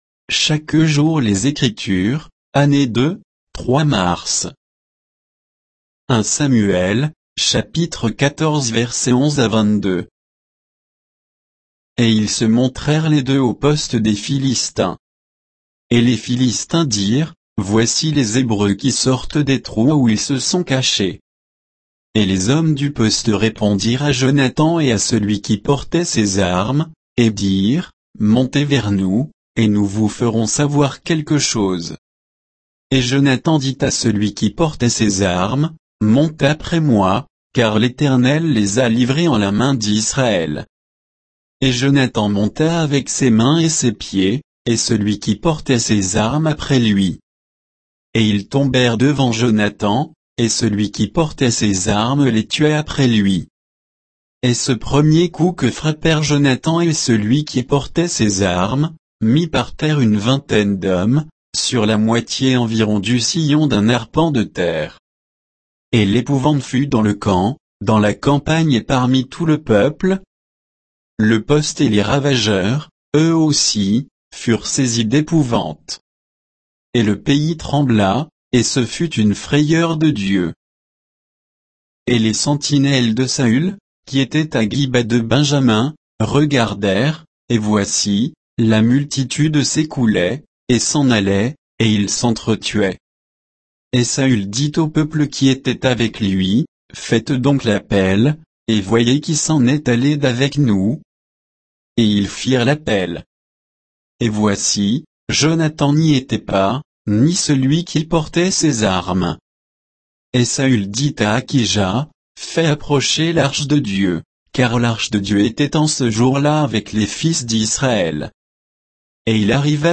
Méditation quoditienne de Chaque jour les Écritures sur 1 Samuel 14